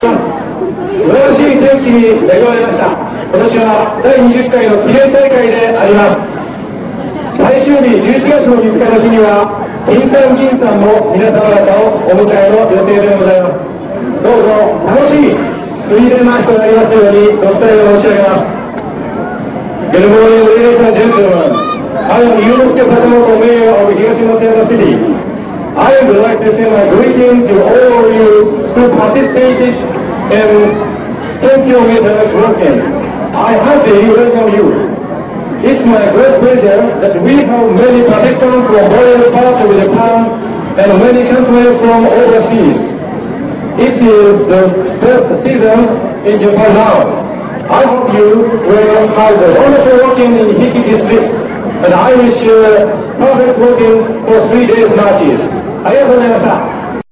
東松山市長の挨拶（市長は英語が上手）(112K)